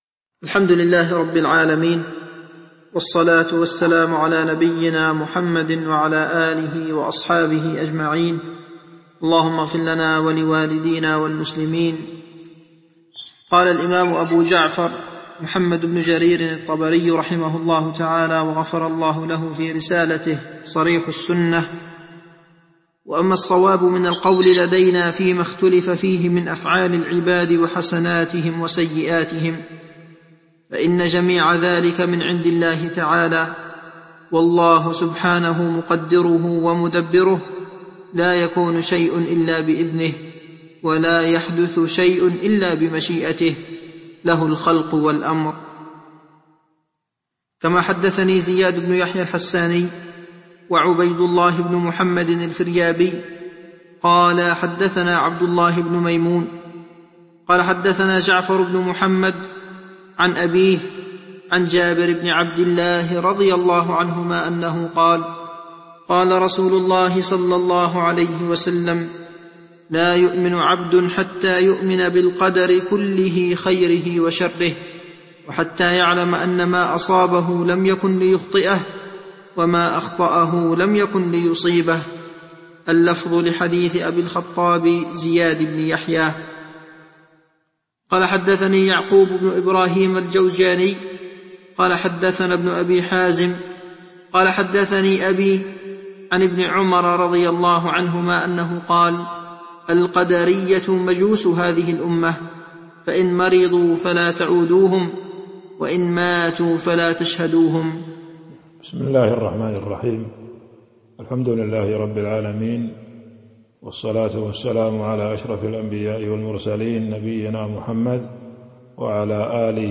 تاريخ النشر ٣ جمادى الآخرة ١٤٤٢ هـ المكان: المسجد النبوي الشيخ